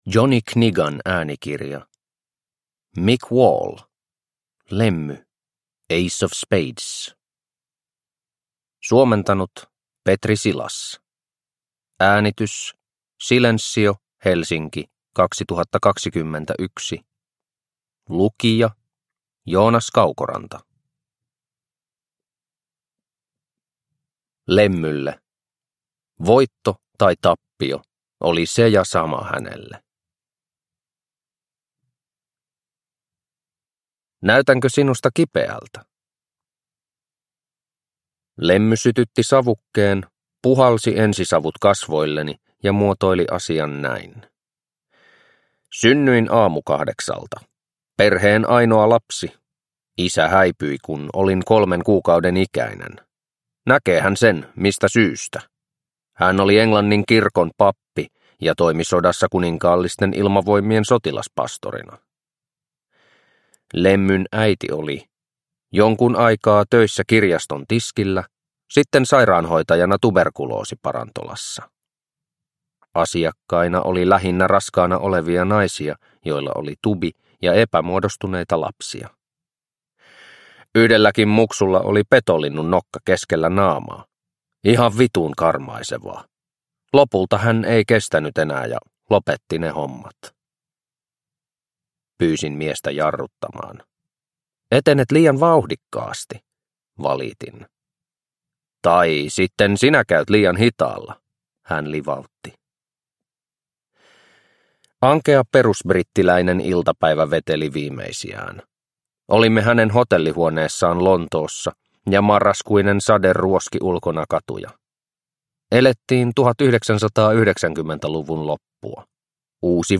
Lemmy – Ljudbok – Laddas ner